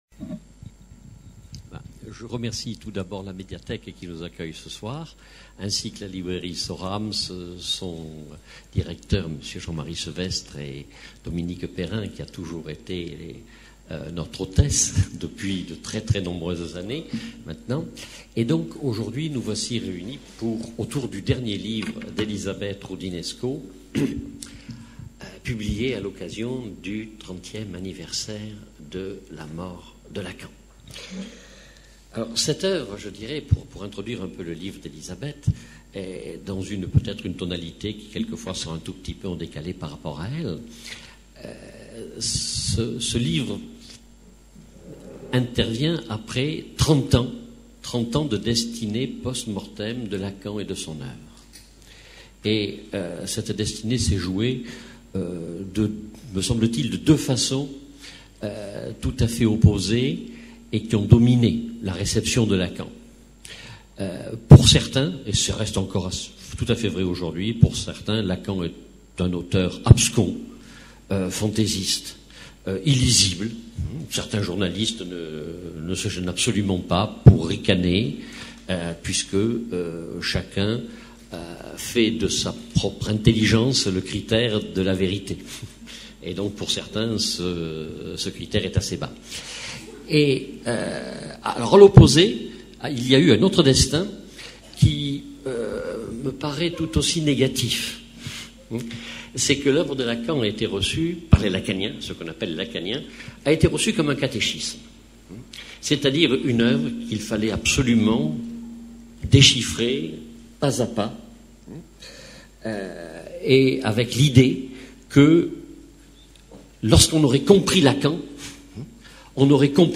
Conférence-débat avec l'historienne et psychanalyste Élisabeth Roudinesco